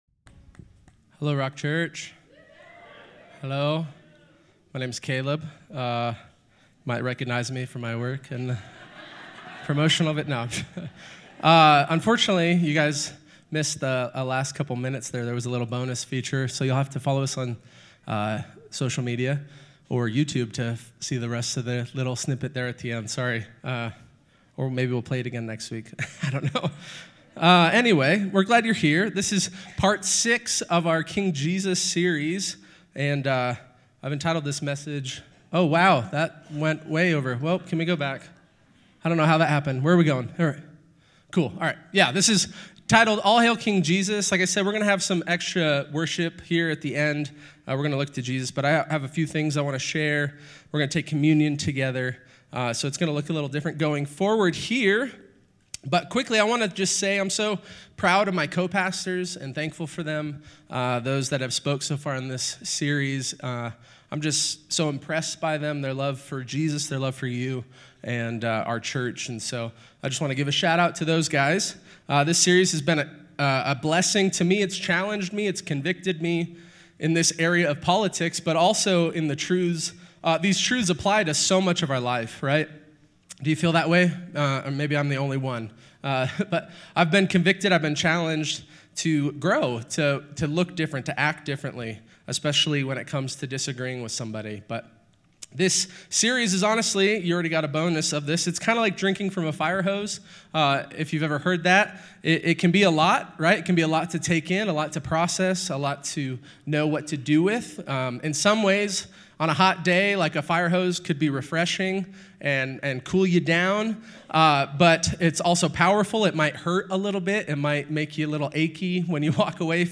As we conclude this series, we will look in two directions: back at what we have learned in this series and forward to the King. We will pray, worship Jesus, and take communion together.